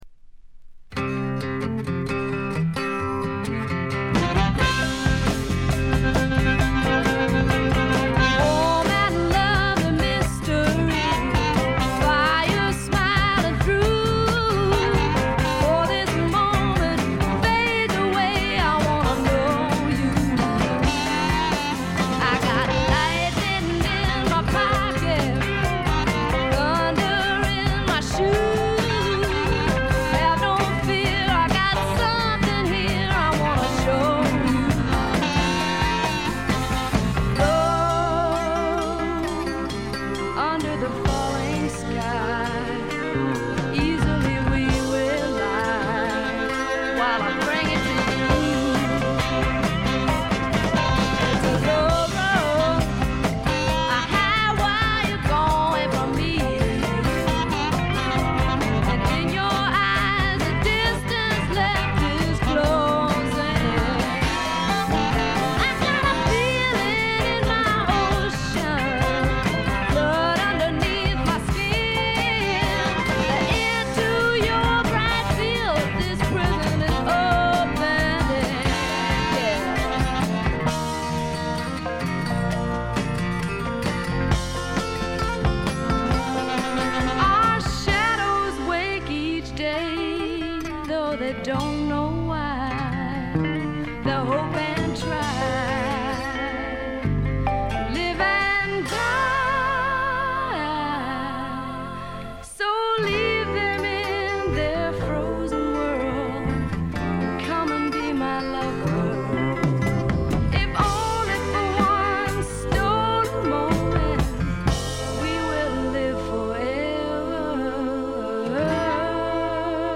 ウッドストック・べアズビル録音の名盤としても有名です。